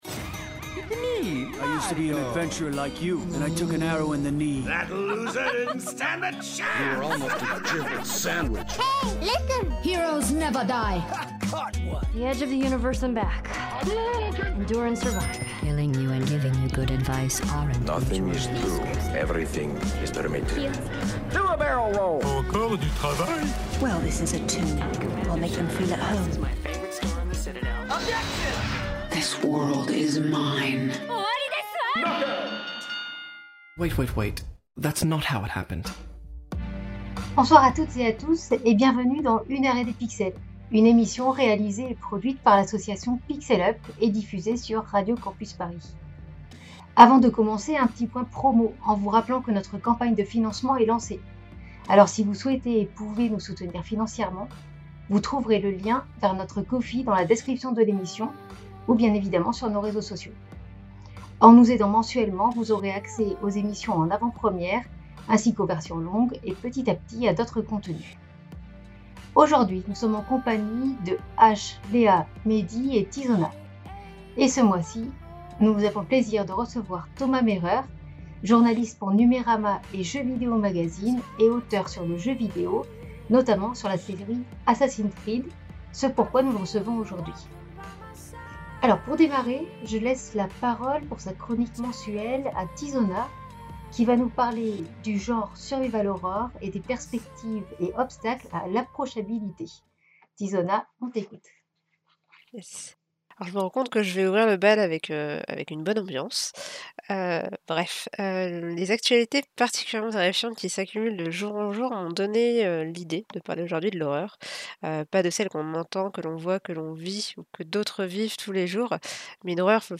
Émission diffusée le 22 février 2025 sur Radio Campus Paris.